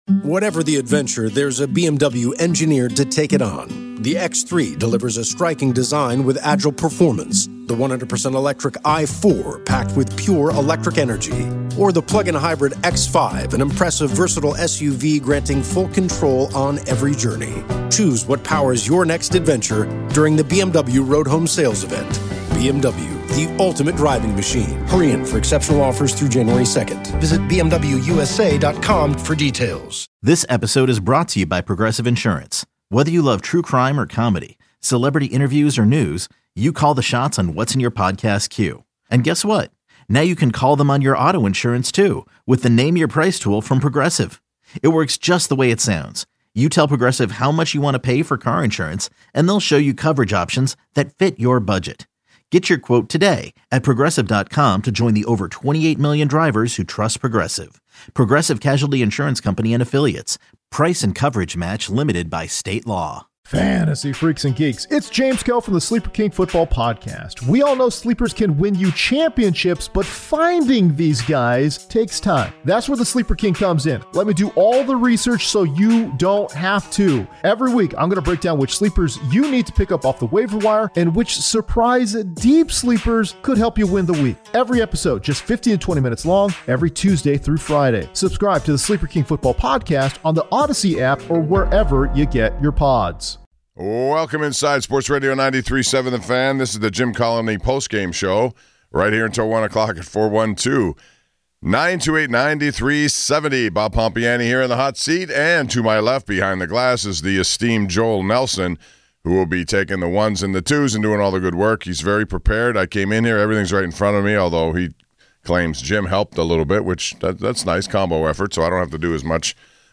A few callers chime in about Steelers-Browns.